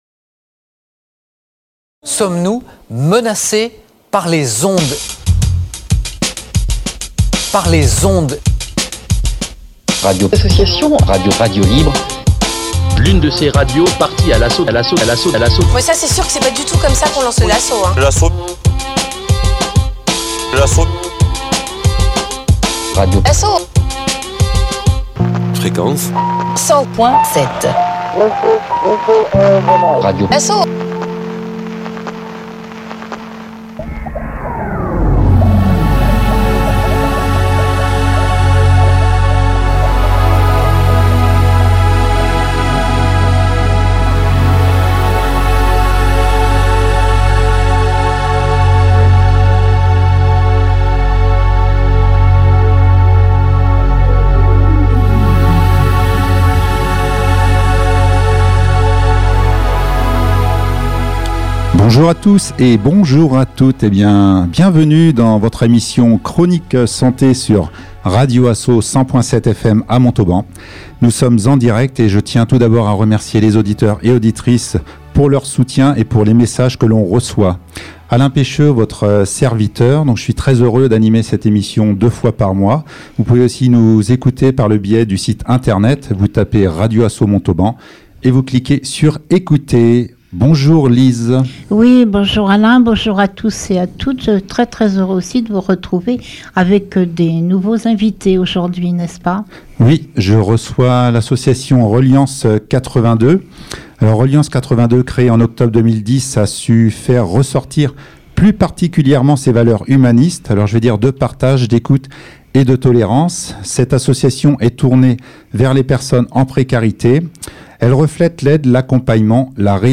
23/01/2018 : Une rubrique dédié à RELIENCE 82 par RADIO ASSO (100.7)
Le 23/01/2018, RADIO ASSO (100.7) nous accueillait afin de mettre en avant notre association et ses différentes actions.